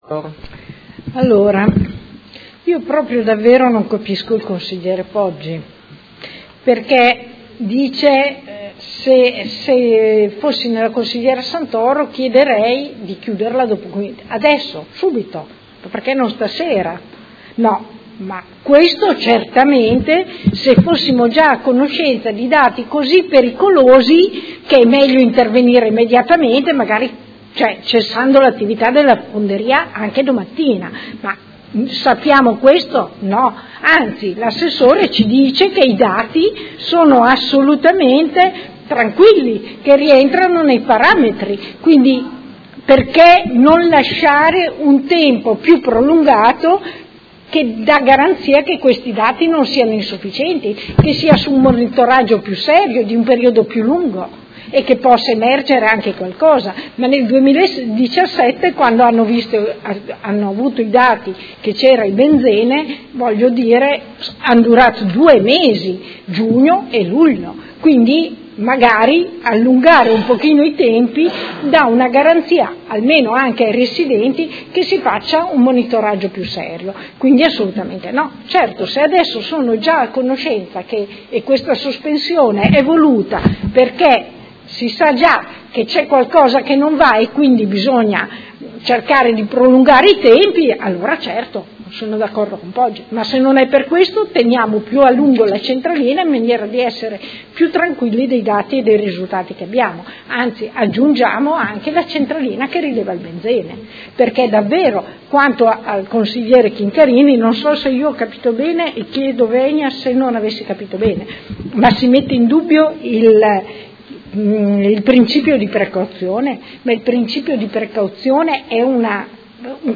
Seduta del 22/11/2018. Dibattito su interrogazioni sul tema delle Fonderie Cooperative di Modena